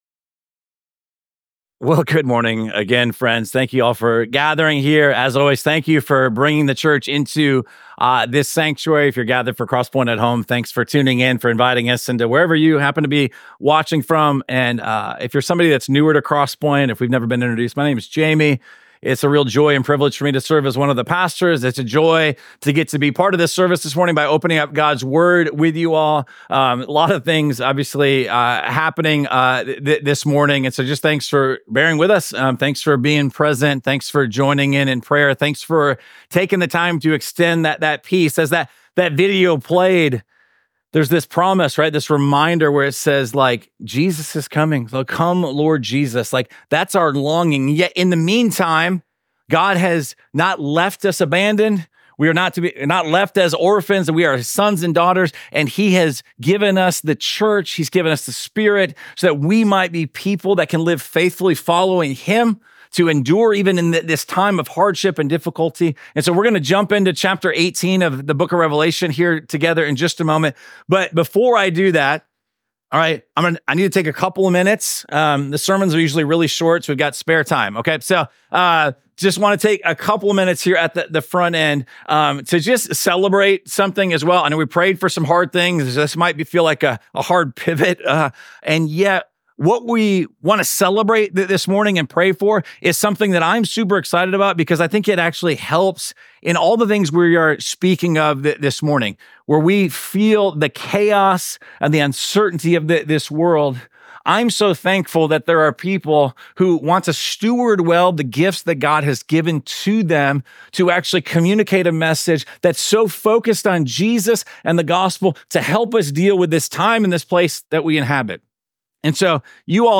Week 13 of our series Jesus Wins: A Study of Revelation. This sermon comes from Revelation chapter 18.